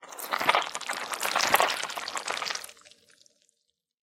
Звук берем несколько пиявок рукой из коробки руки в перчатках